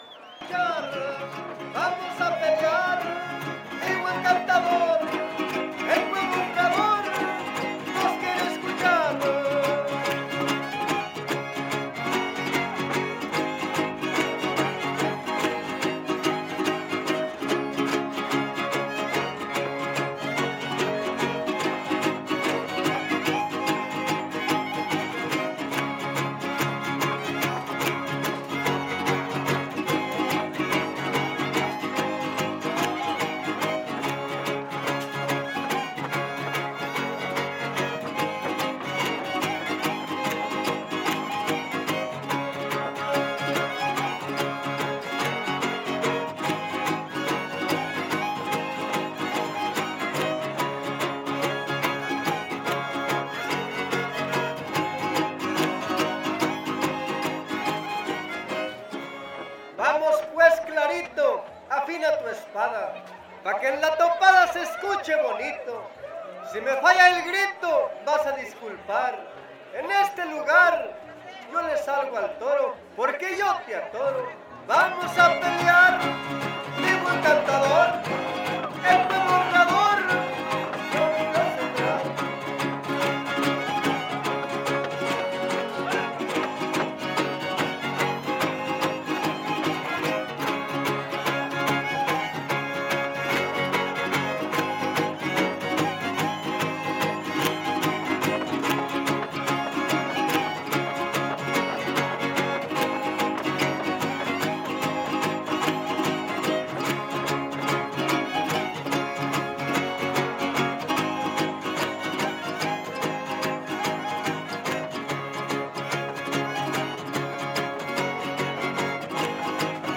Décima Huapango arribeño
No identificado (violín segundo)
Violín Vihuela Guitarra
Topada ejidal: Cárdenas, San Luis Potosí